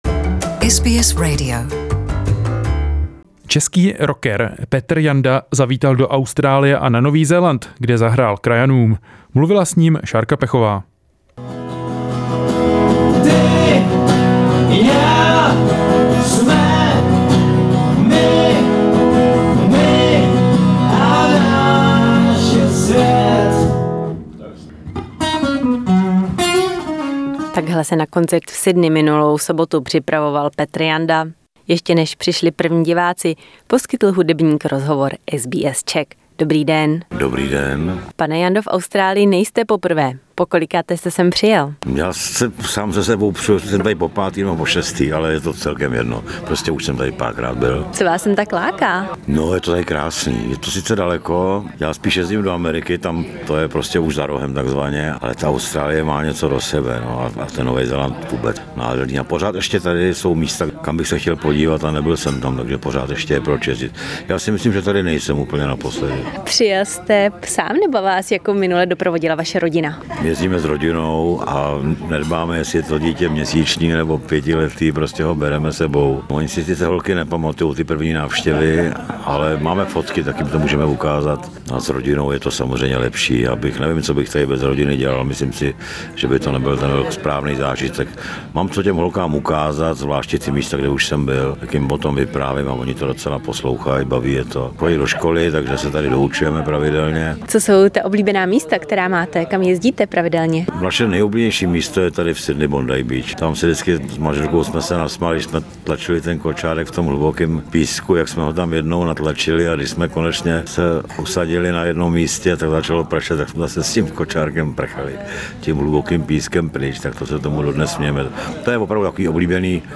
Janda spoke to SBS Czech before his concert in Sydney.